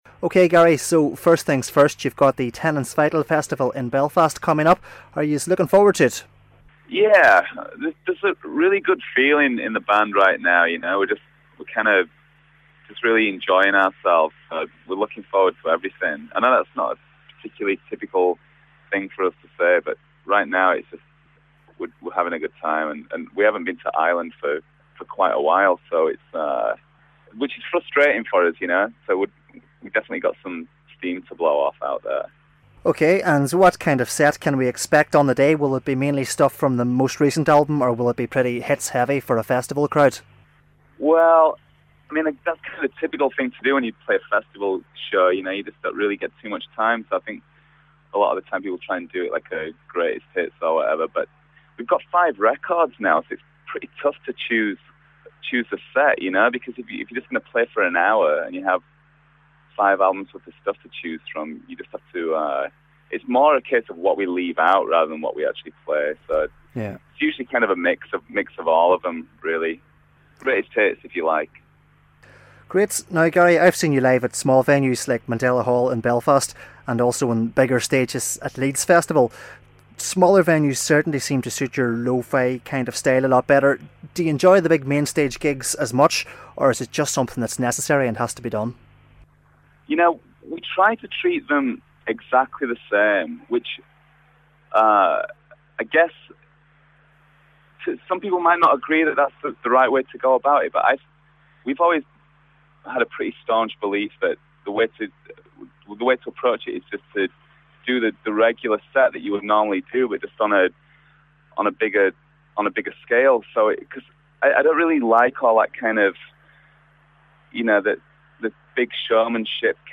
Part 1 of an in depth interview with Gary Jarman from The Cribs ahead of their gig at Tennent's Vital 2012. Here he talks about the upcoming T-Vital gig, the mood in the band at present, and how they cope with playing on the main stages